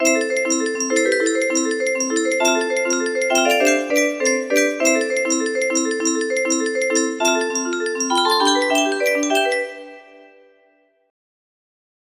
Low tempo